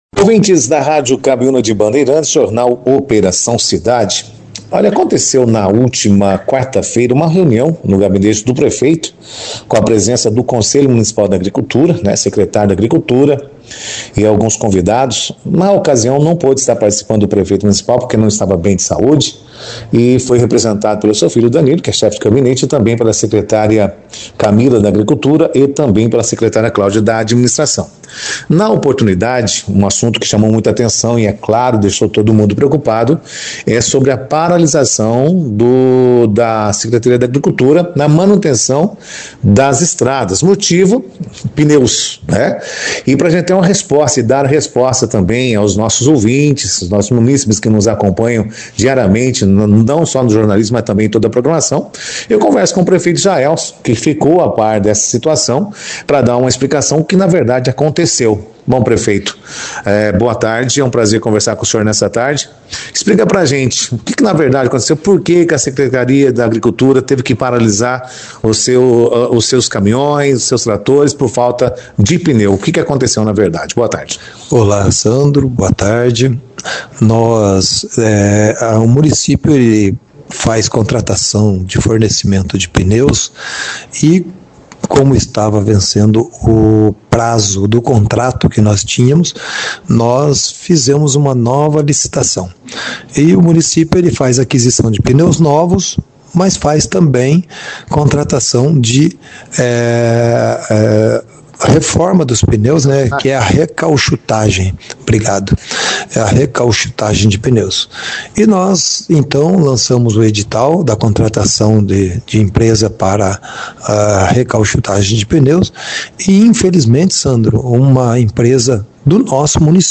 Prefeito fala sobre falta de pneus que paralisa manutenção de estradas rurais em Bandeirantes - Rádio Cabiuna
Durante o encontro, um tema chamou a atenção e gerou preocupação entre os participantes: a paralisação temporária dos serviços de manutenção das estradas rurais, devido à falta de pneus para a frota da Secretaria da Agricultura. Na 2ª edição do jornal Operação Cidade desta sexta-feira, 31, o prefeito Jaelson Matta, esclareceu a situação, e explicou sobre esta paralisação por falta de pneus.